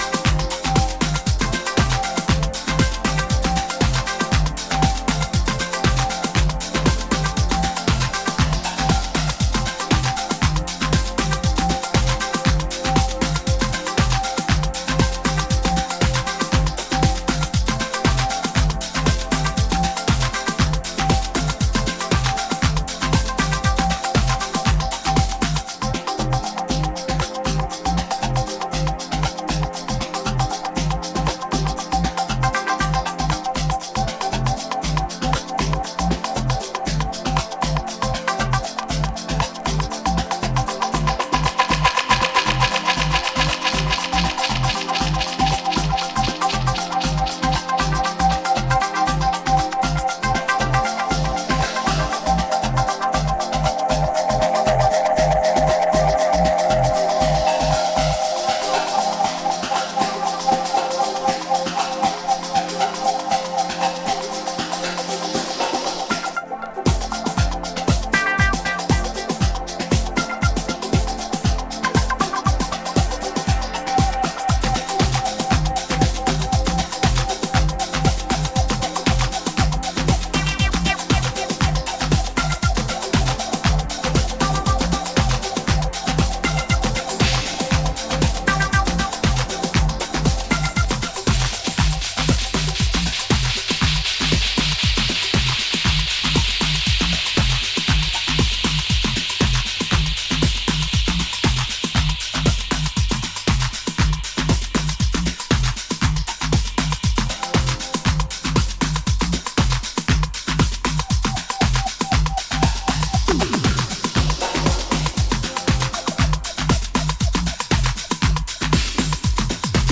メランコリックなメロディーと加工されたハイハットがどこまでも続いていきそうな